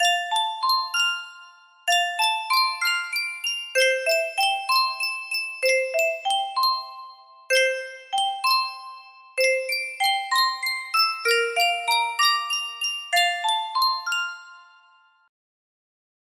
Yunsheng Music Box - Under the Anheuser Bush 6590 music box melody
Full range 60